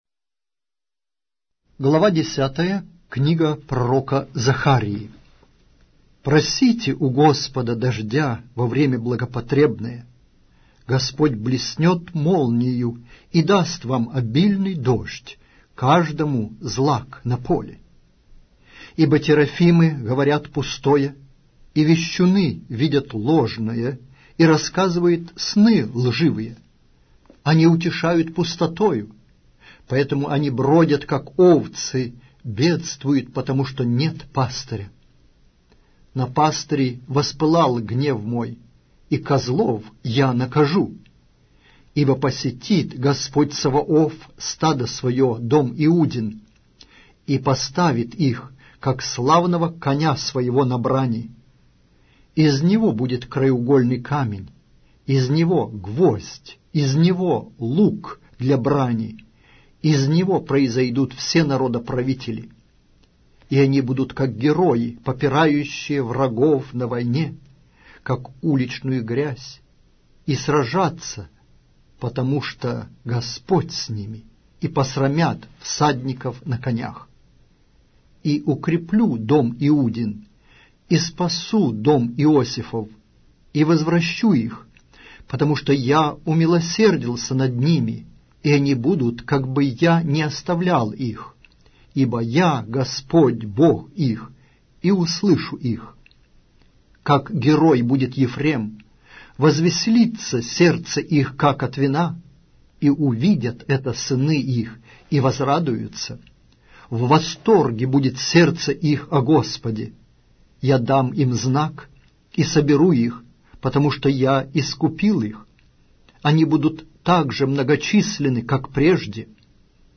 Аудиокнига: Пророк Захария